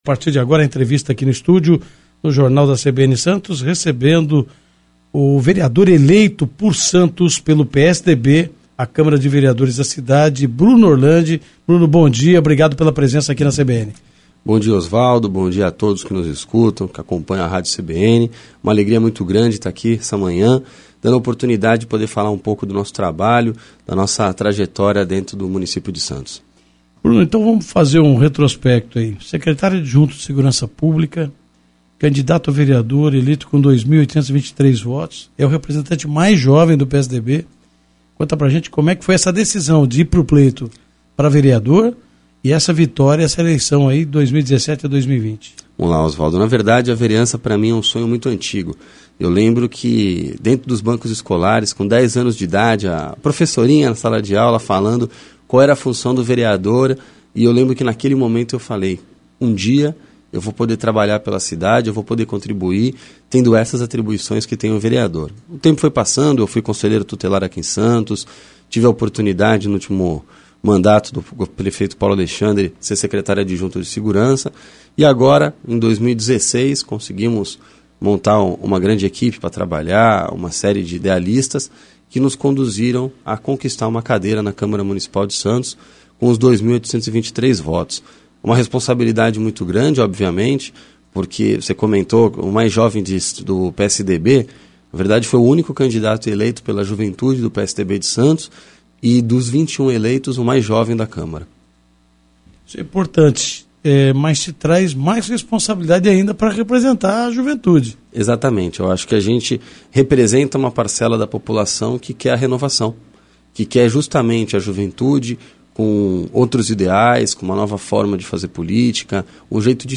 Entrevista Rádio CBN Santos - Bruno Orlandi
Entrevista para a Rádio CBN Santos concedida pelo vereador Bruno Orlandi em 15/11/2016.